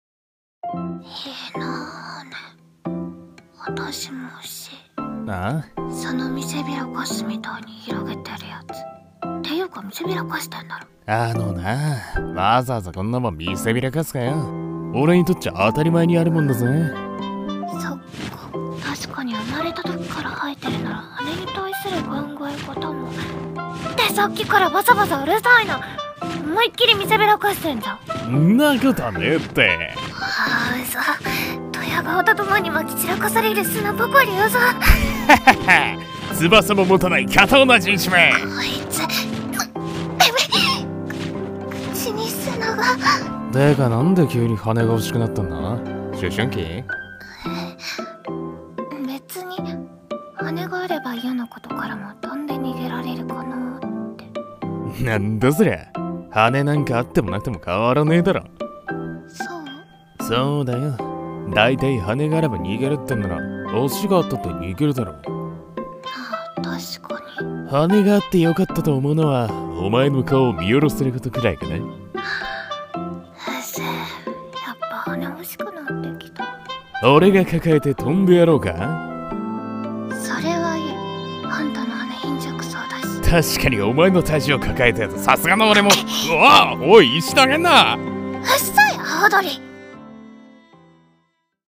声劇台本【確かな翼の活用法】